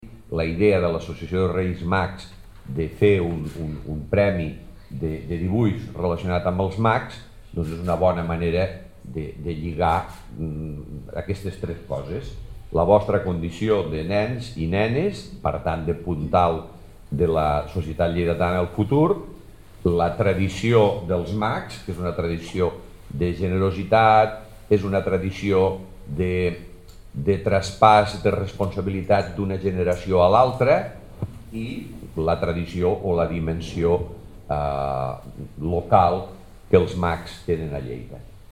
tall-de-veu-de-lalcalde-miquel-pueyo-sobre-el-xix-concurs-de-dibuix-dels-reis-mags-de-lleida